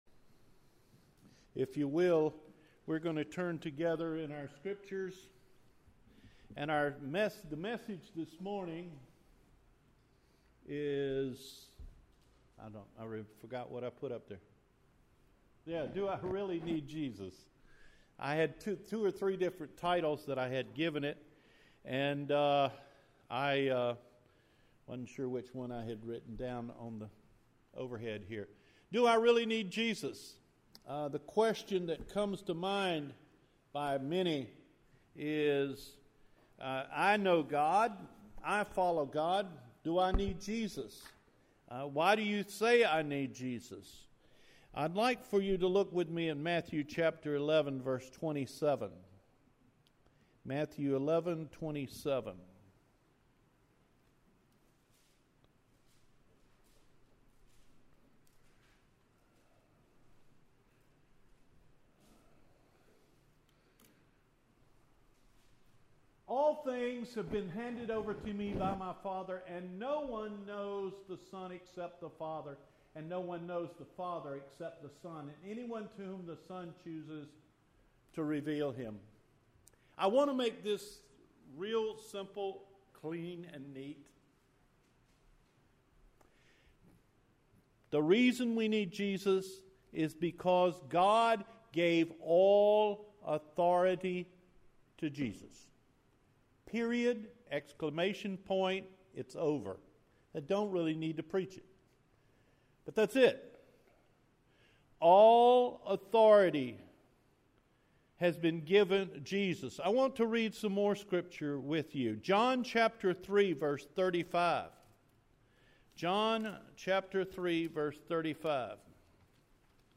Do I Really Need Jesus? – July 22 Sermon